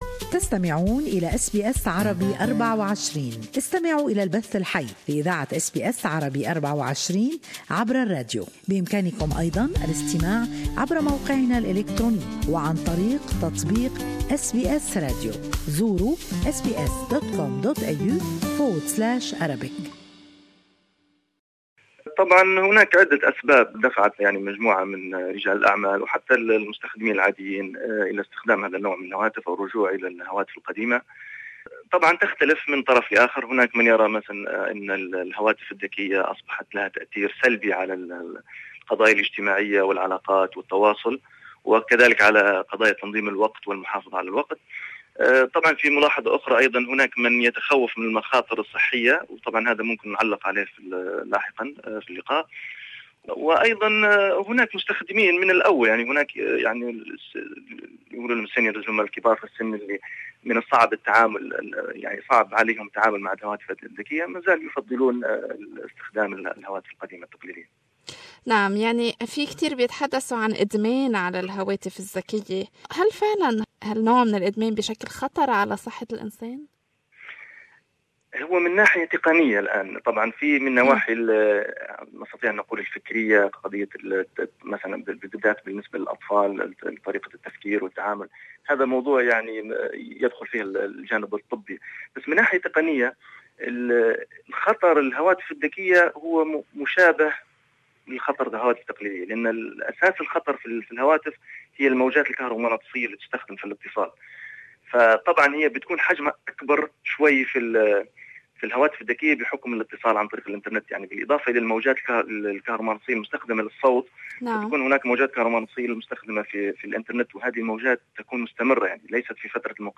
SKIP ADVERTISEMENT لسماع تفاصيل المقابلة يمكنكم الضغط على التدوين الصوتي أعلاه.